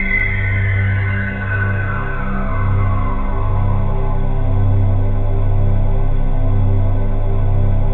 ATMOPAD16 -LR.wav